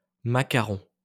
A macaron (/ˌmækəˈrɒn/ MAK-ə-RON,[1][2] French: [makaʁɔ̃]